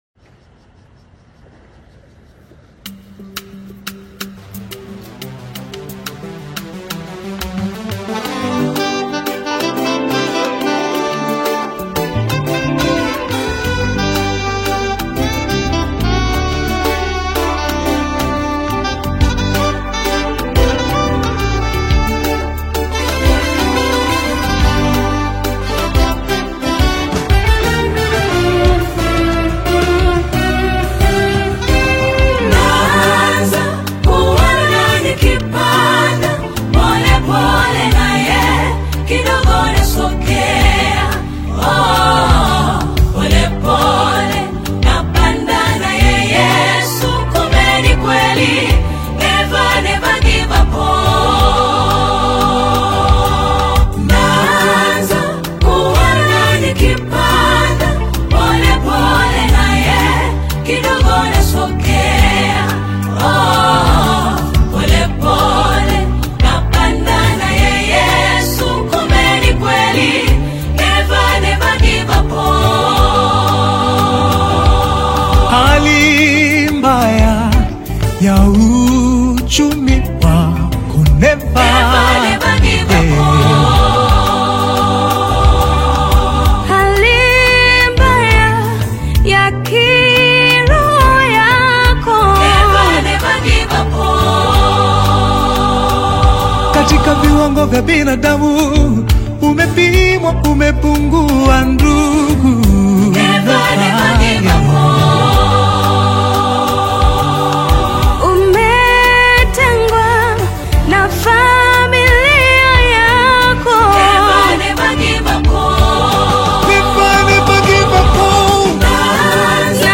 AudioGospel
Tanzanian Afro-Pop single
delivering uplifting rhythms and heartfelt vocals.